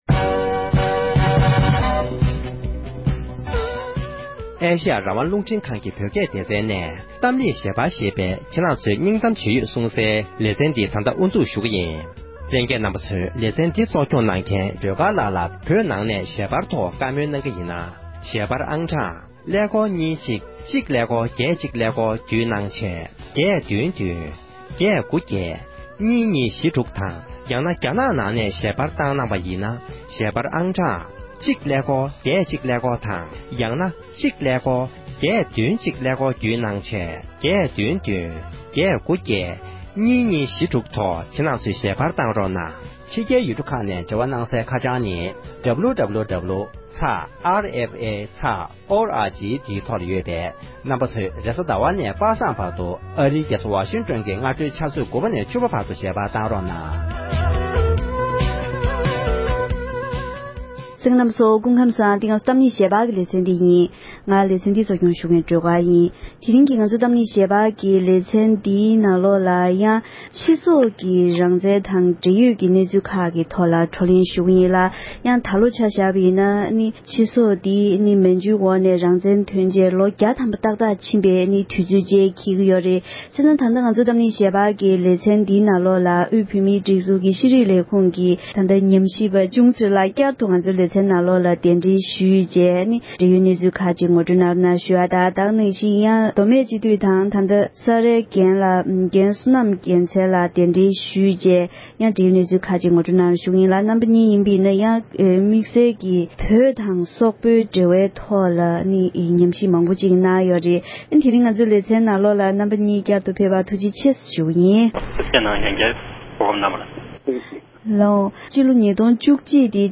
སོག་ཡུལ་གྱི་གནས་སྟངས་དང་དེ་བཞིན་བོད་དང་འབྲེལ་ལམ་གང་འདྲ་ཡོད་མེད་ཐད་འབྲེལ་ཡོད་མི་སྣ་དང་བཀའ་མོལ་ཞུས་པའི་དུམ་བུ་དང་པོ།